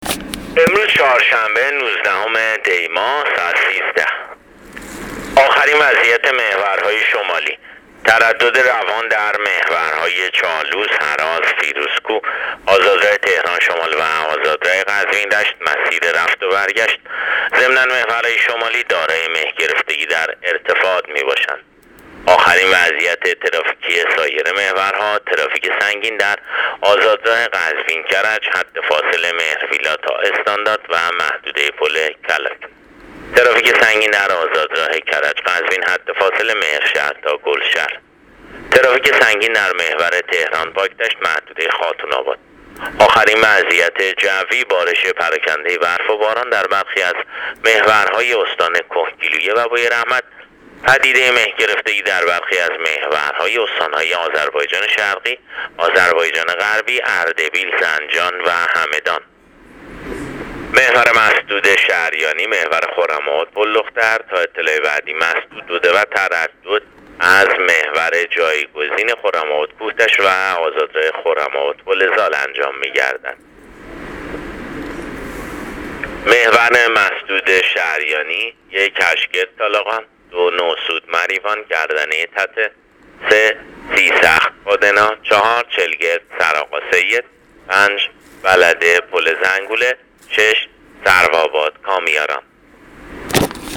گزارش رادیو اینترنتی از آخرین وضعیت ترافیکی جاده‌ها تا ساعت ۱۳ نوزدهم دی؛